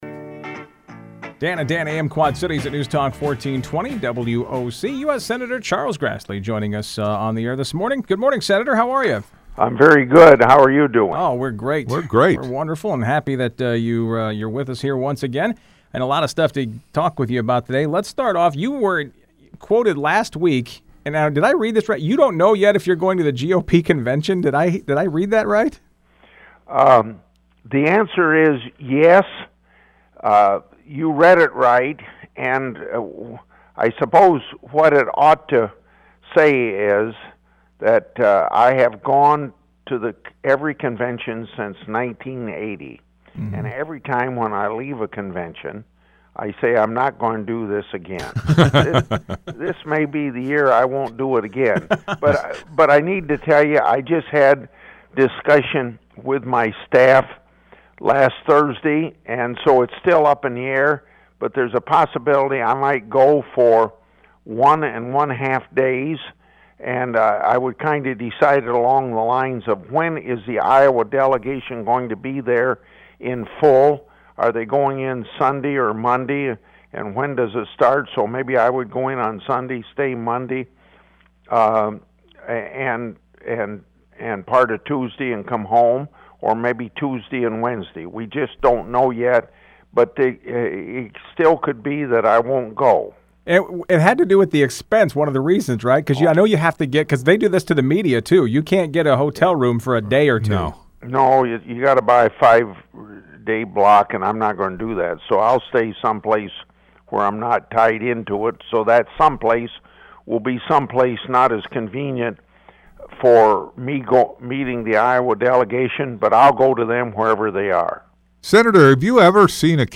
Public Affairs Program, WOC, 4-25-16.mp3